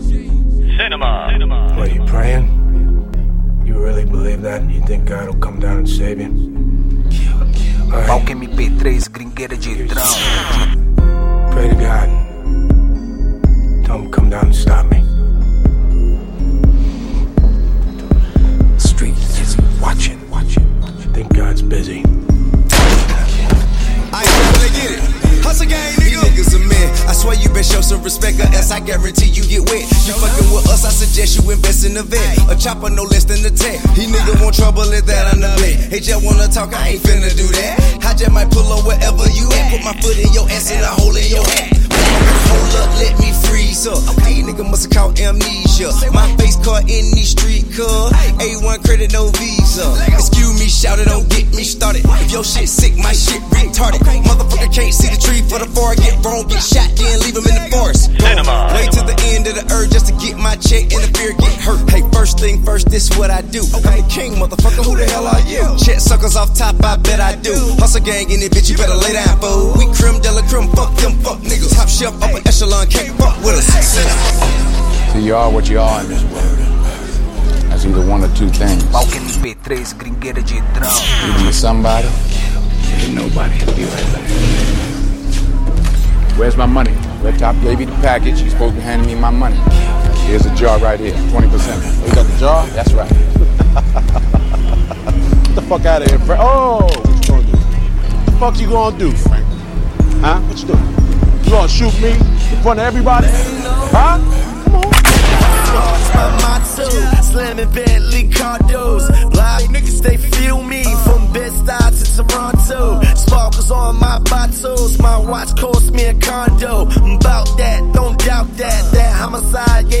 rap nacional